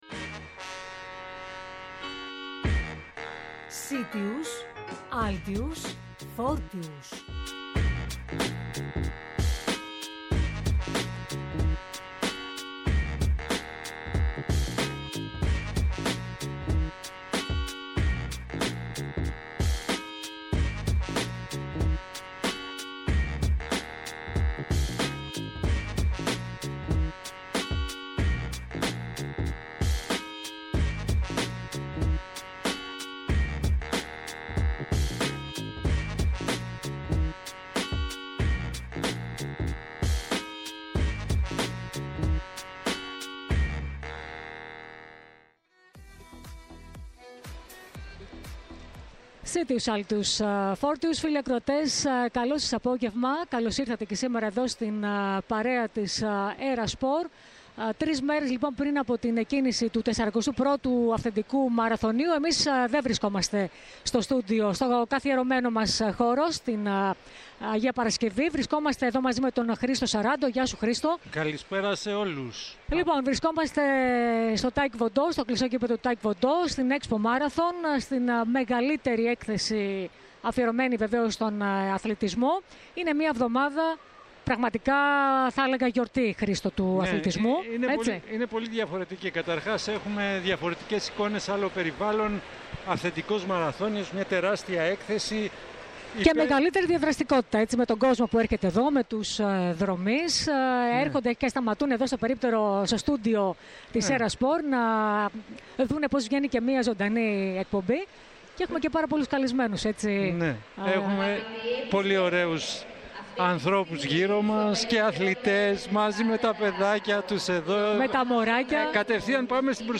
Από την EXPO MARATHON